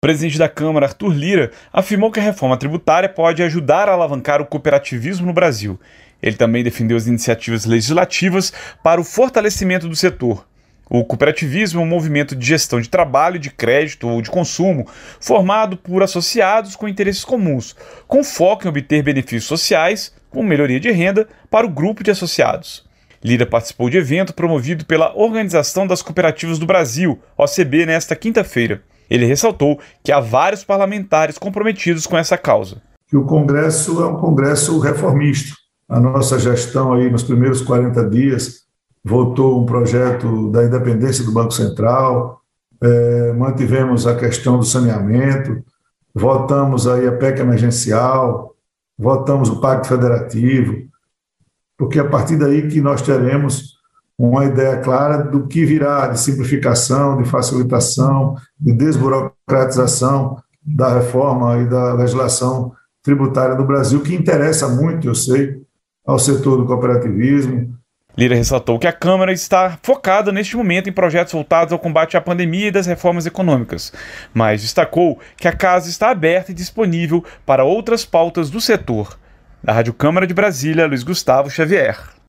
O presidente da Câmara participou de evento do setor nesta quinta-feira